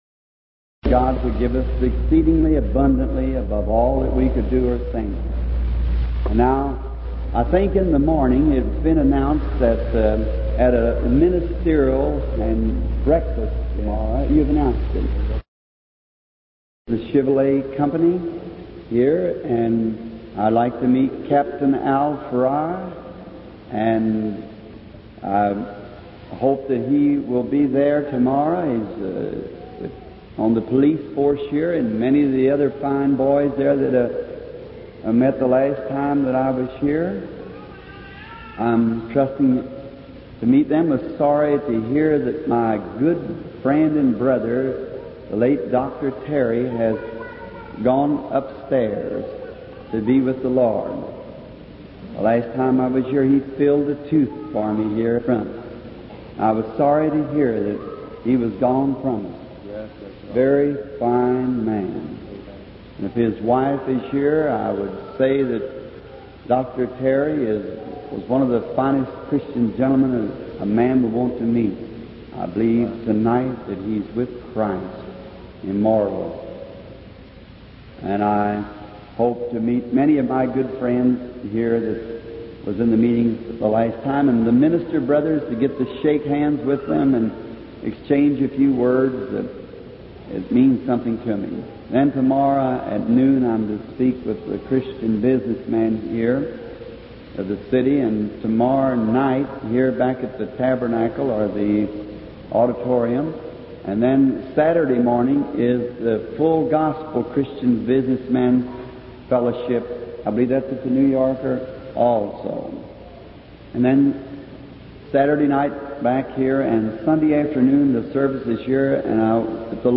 aufgezeichneten Predigten